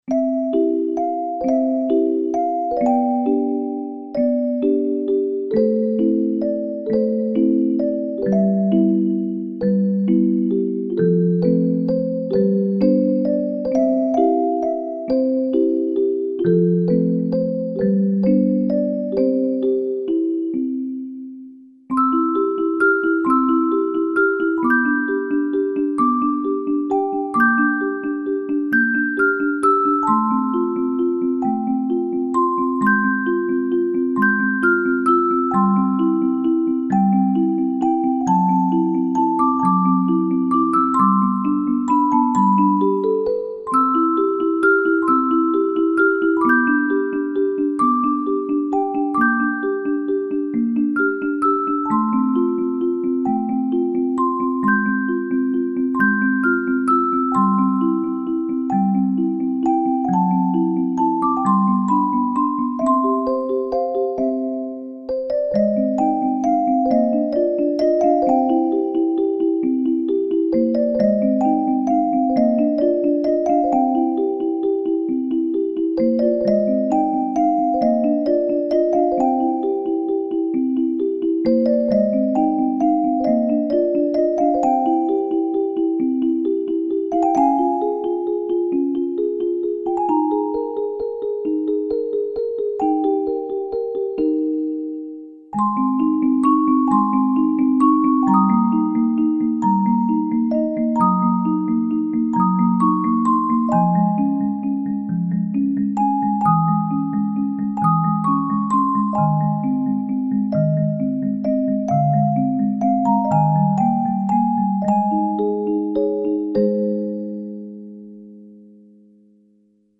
オルゴール曲素材
かわいい曲。